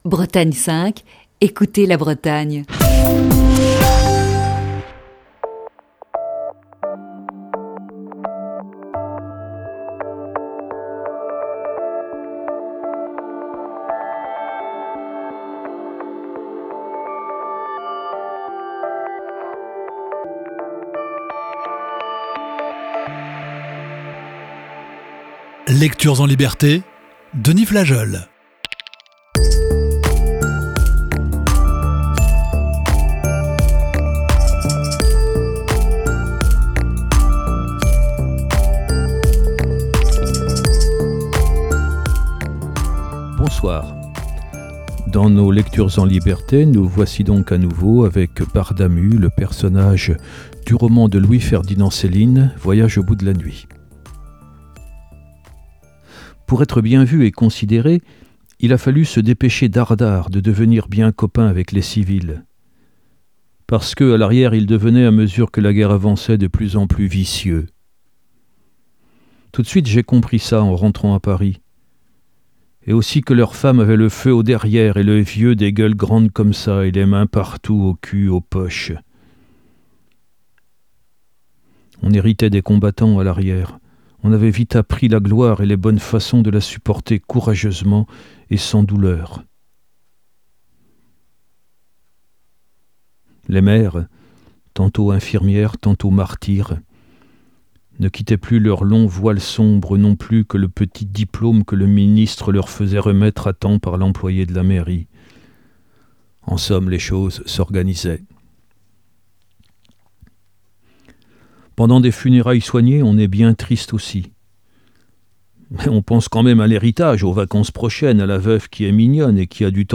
Émission du 7 février 2020.